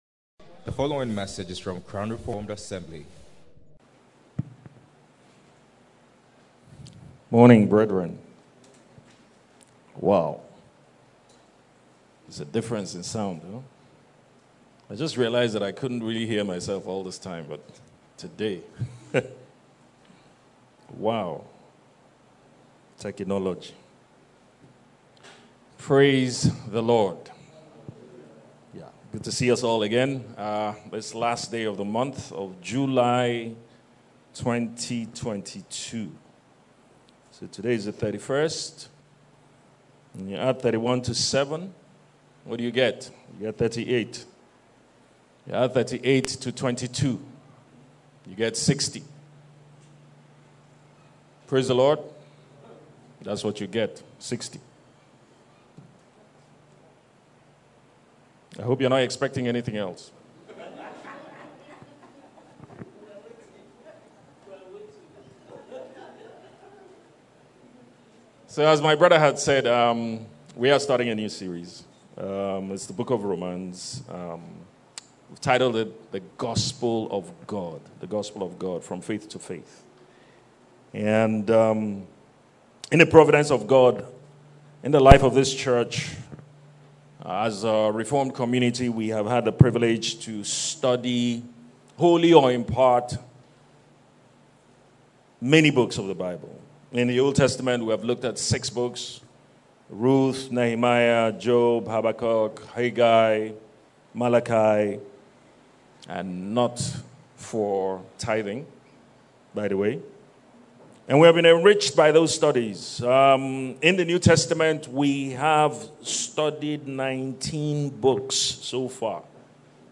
Series: Sunday Service, The Book Of Romans